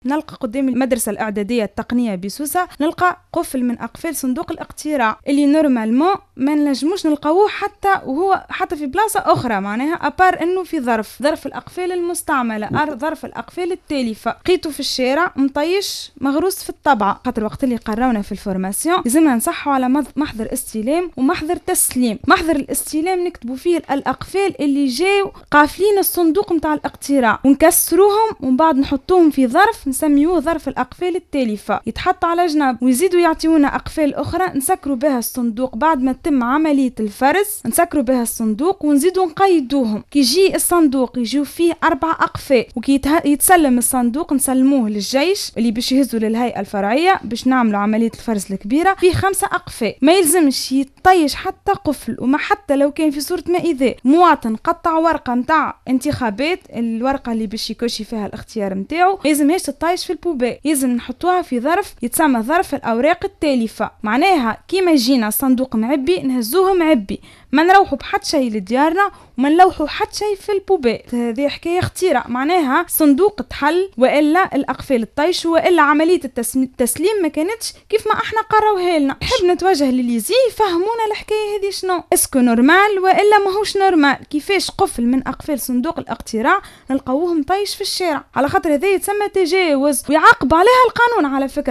Un membre d'un des bureaux de vote à Sousse, a annoncé ce lundi 27 octobre 2014 sur les ondes de Jawhara FM, avoir trouvé l’une des serrures (cadenas) des urnes relatives aux élections législatives, jetée dans la rue à Sousse (dont les photos sont affichées dans l'article).